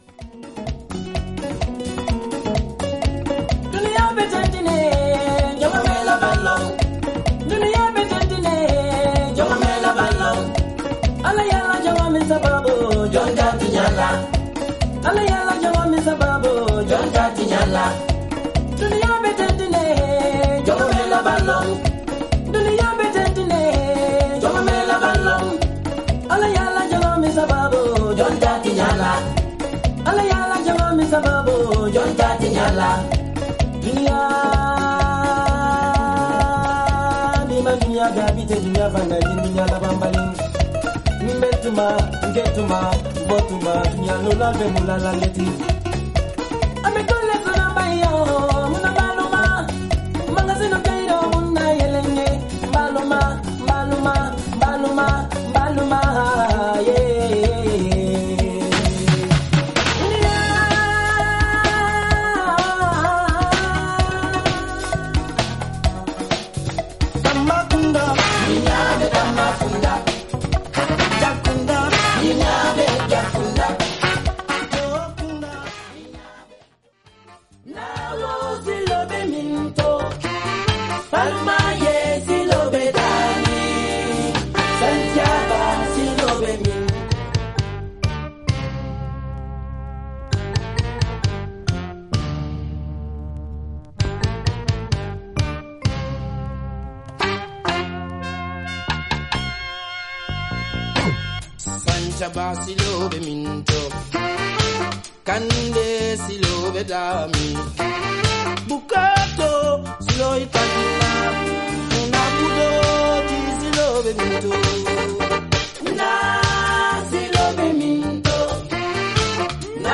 フロア・ライクなエレクトリック・アフロ・ファンク
哀愁系メロウ・ナンバー
様々なエレメントがクロスオーバーする、アフロ・ニューウェイヴの傑作です。